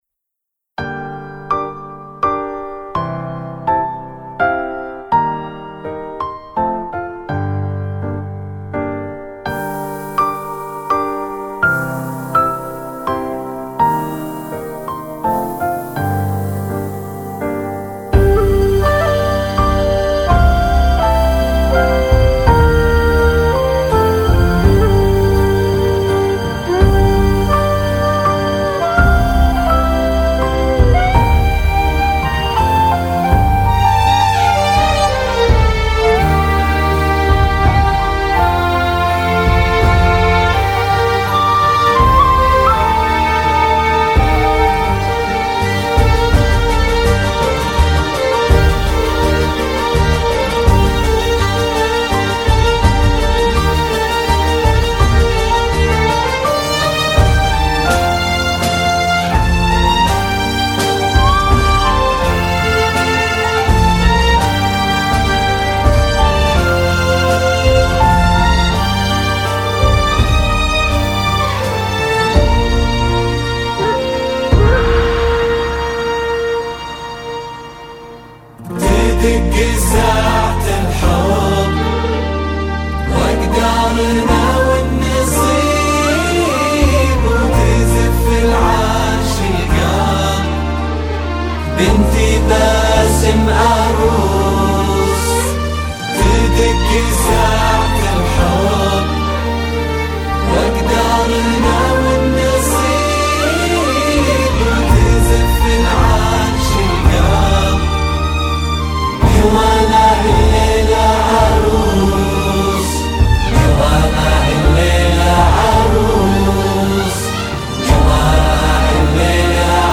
اكبر موقع زفات في السعودية والخليجية لحفلات الزفاف.